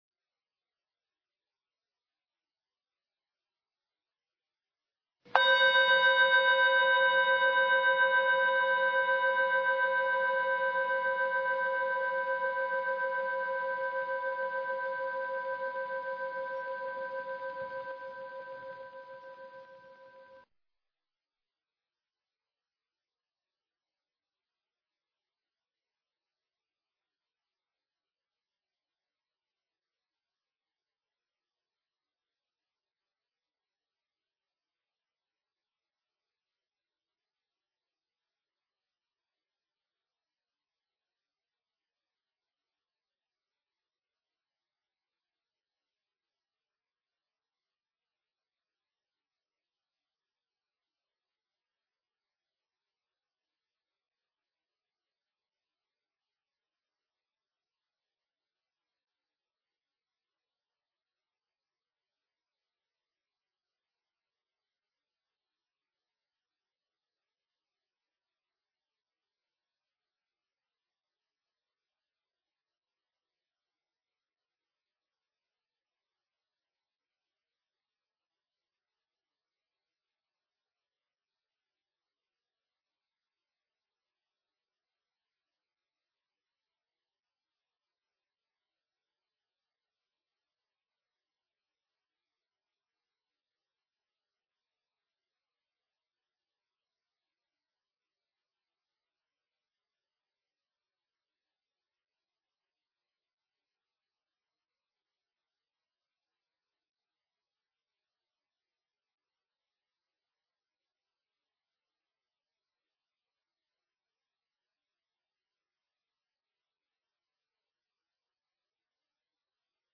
Tiếng chuông đầu tiên thông báo để vào thư giản và điều thân. 2.
Tiếng chuông thứ nhì thông báo vào thư giản và quán số điện tử. 3.
Ba tiếng chuông liên tiếp cuối cùng là Hồi Hướng và Xả Thiền.